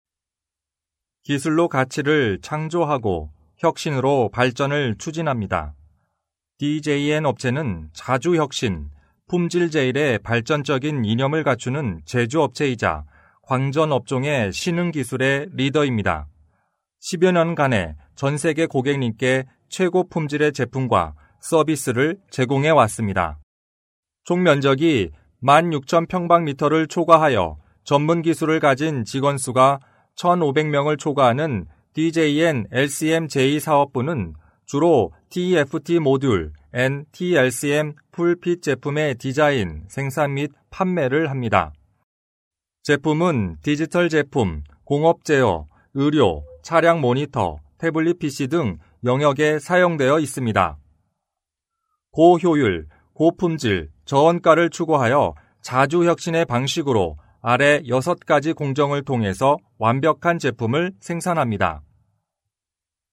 3 韩语男3_外语_小语种_韩明宣传片类160每分_年轻 韩语男3
韩语男3_外语_小语种_韩明宣传片类160每分_年轻.mp3